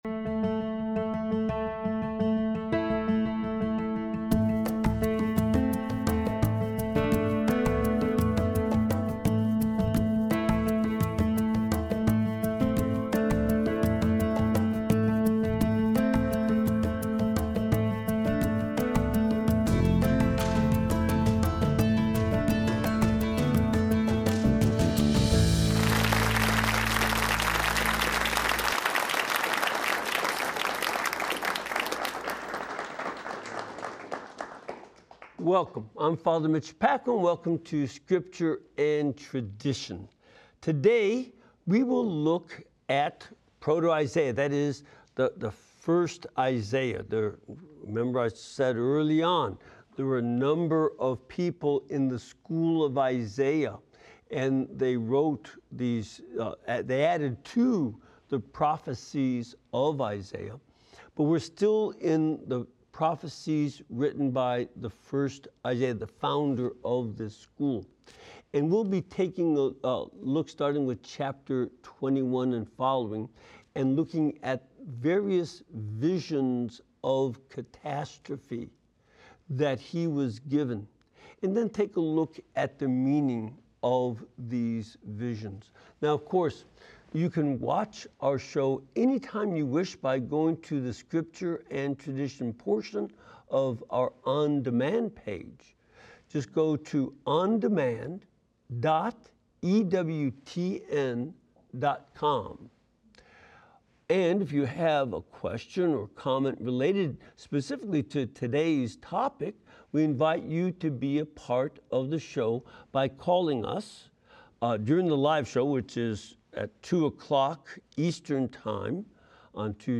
analyzes Church Traditions and Teachings in light of Sacred Scripture during this weekly live program.